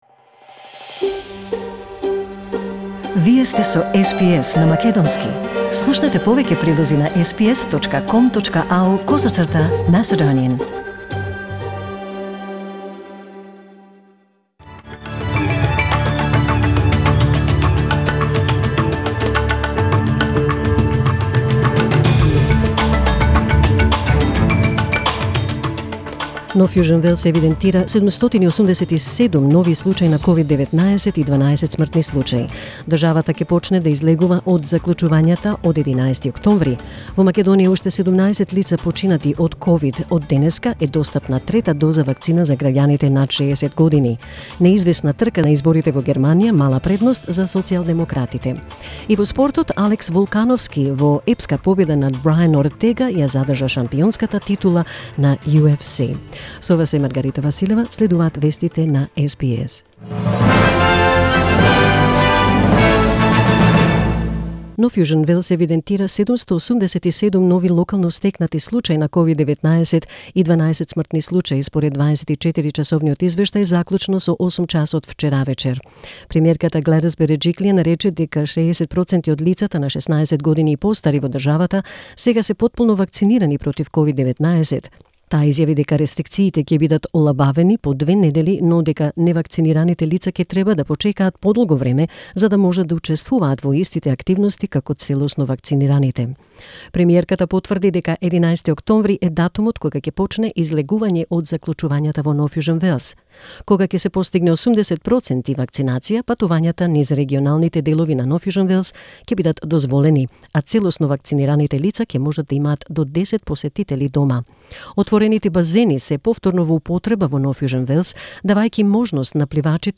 SBS News in Macedonian 27 September 2021